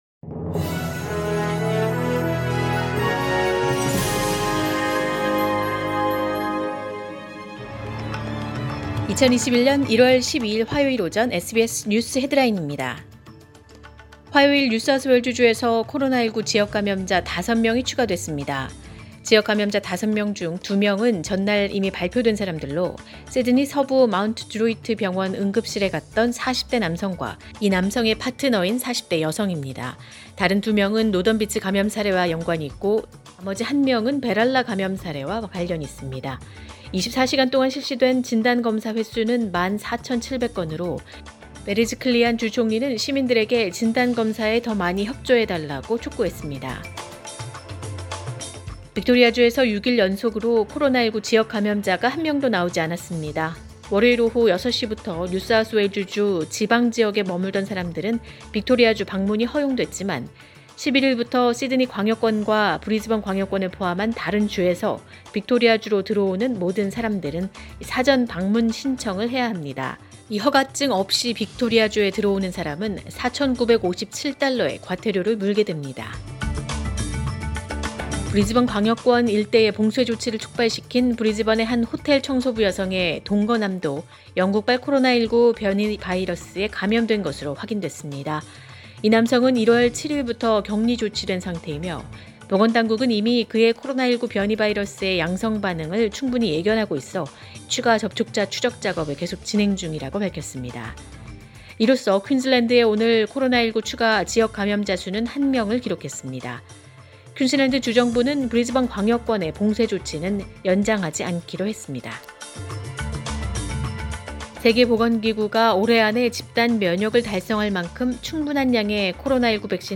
2021년 1월 12일 화요일 오전의 SBS 뉴스 헤드라인입니다.